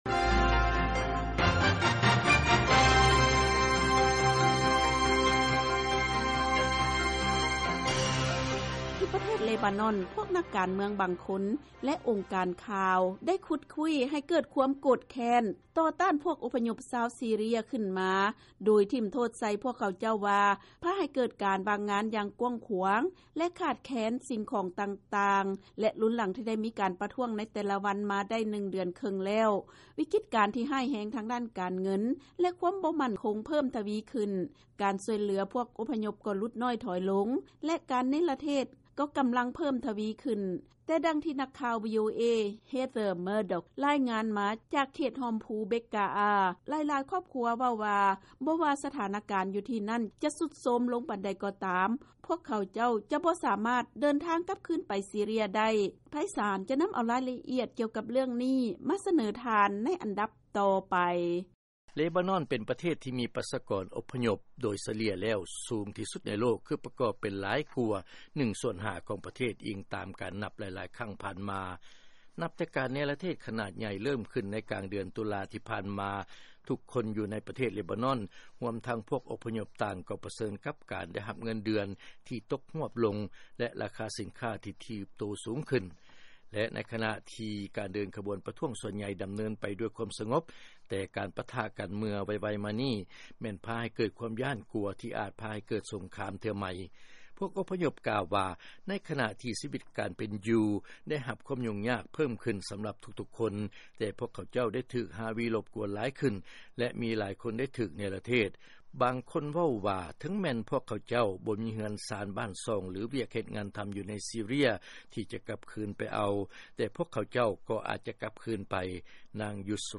ເຊີນຟັງລາຍງານ ຂະນະທີ່ ວິກິດການ ໃນເລບານອນ ຍັງດຳເນີນຕໍ່ໄປ ພວກອົບພະຍົບຈາກຊີເຣຍ ກໍມີຄວາມຢ້ານກົວຫຼາຍຂຶ້ນ